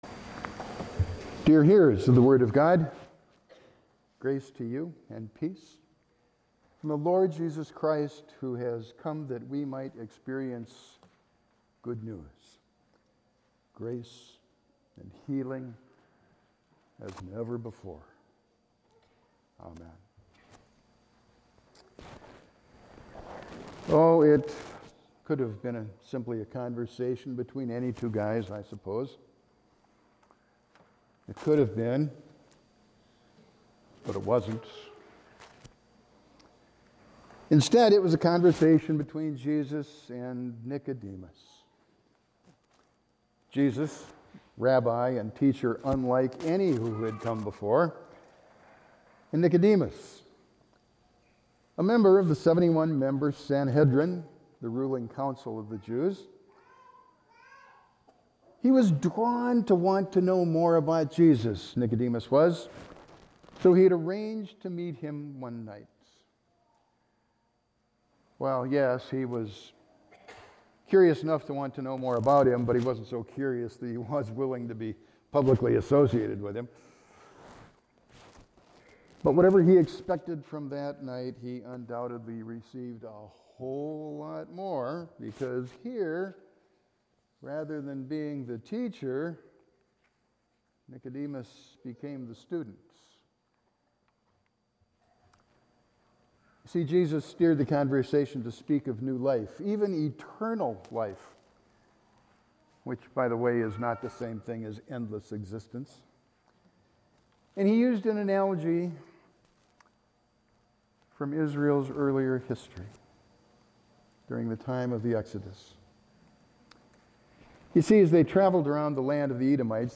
Sermons 2024 - Bethesda Lutheran Church